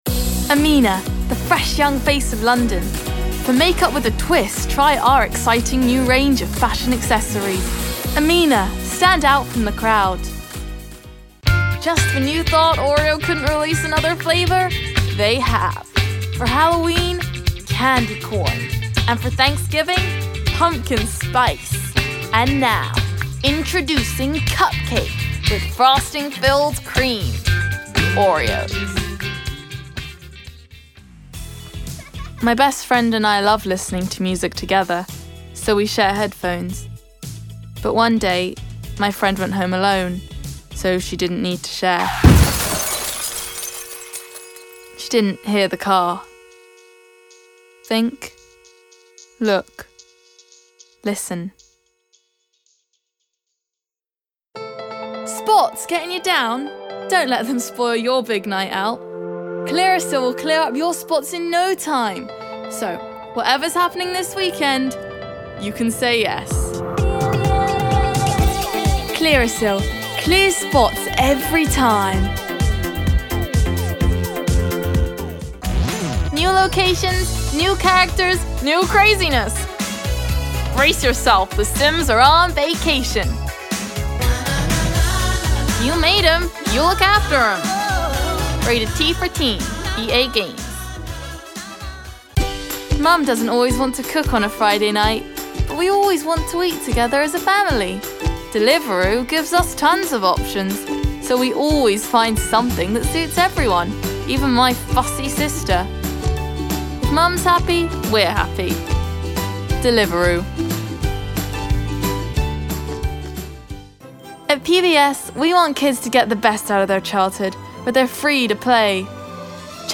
Gender Female
Accent Essex Irish London Standard English R P Standard U S
Characters & Animation
Showreel, young voicework. Happy, Uplifting